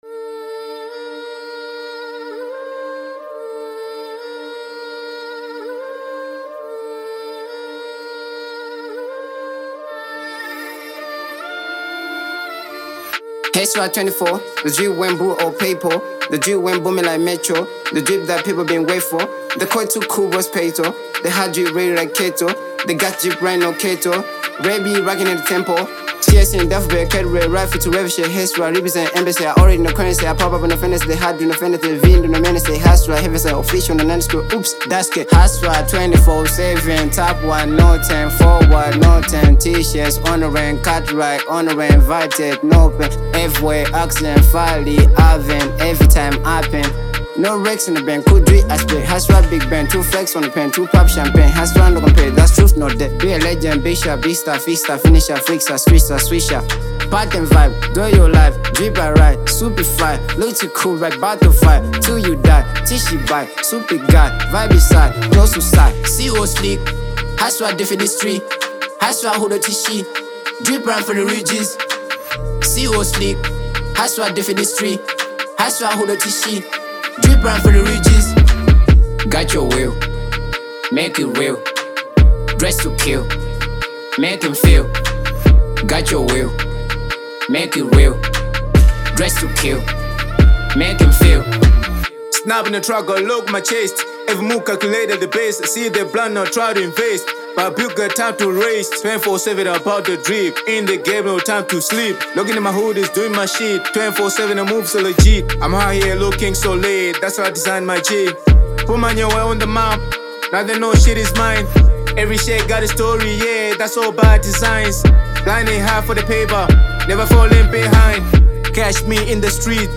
Genre : Drill/Hiphop